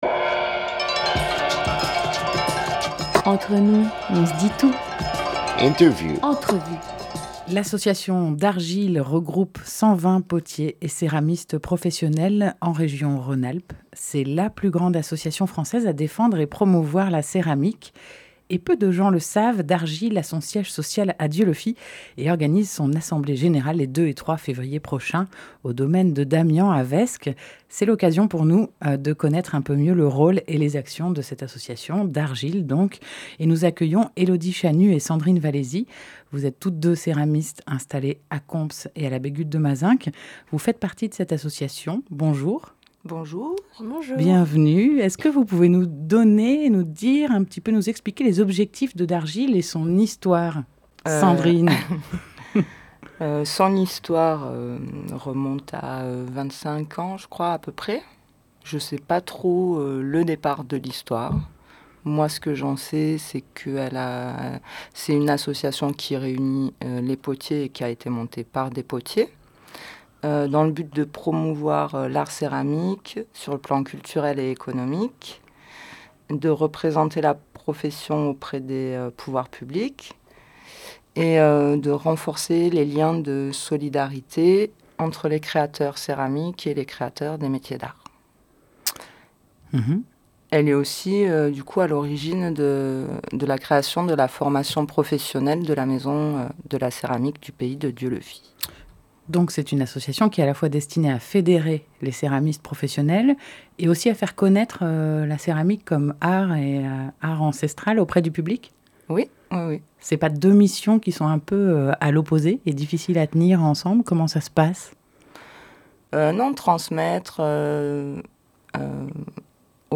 1 février 2018 12:00 | Interview